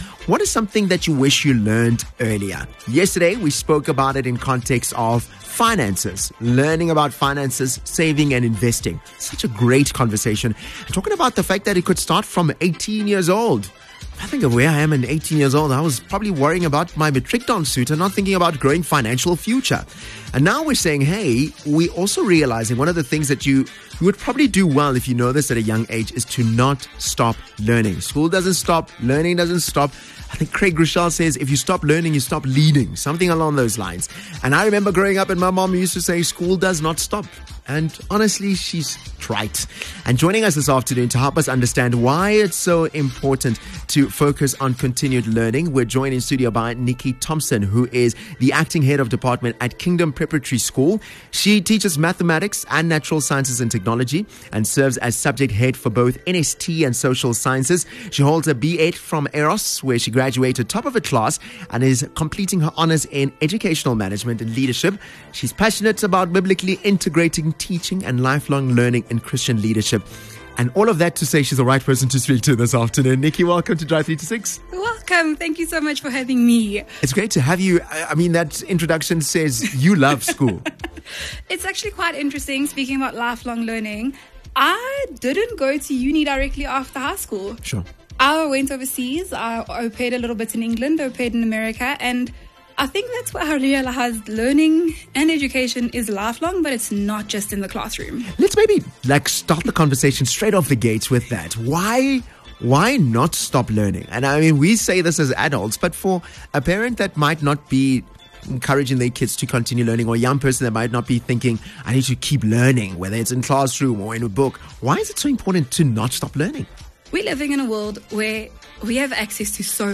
In this insightful conversation, they discuss how fostering a lifelong love for learning can shape future success and unlock potential in and beyond the classroom.